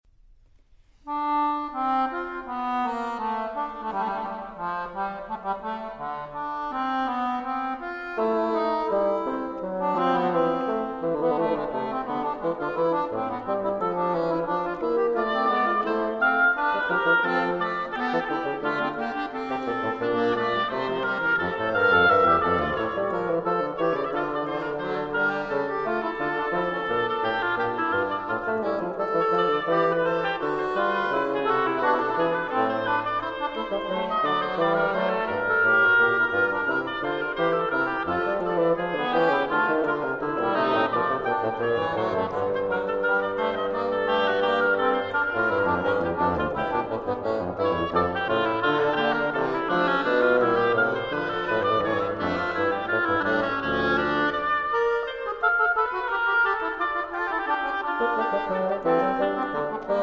oboe
accordion
bassoon